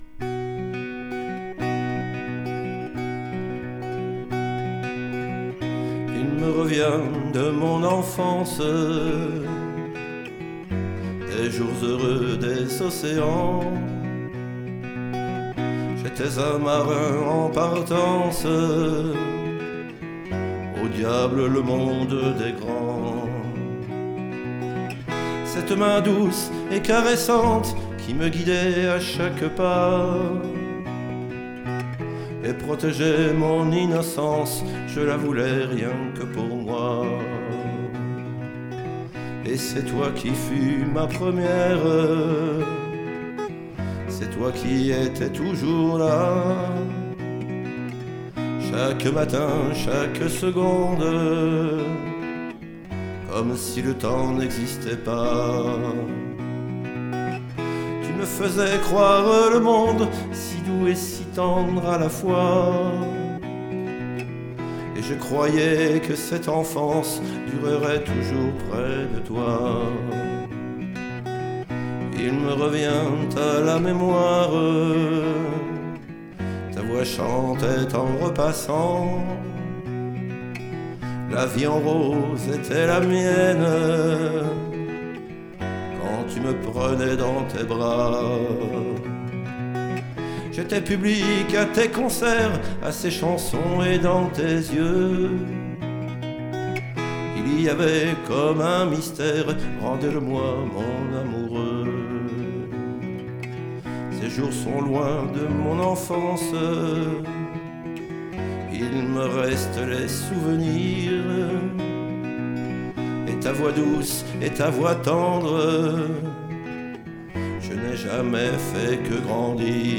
Musique et chant